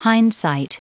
Prononciation du mot hindsight en anglais (fichier audio)
Prononciation du mot : hindsight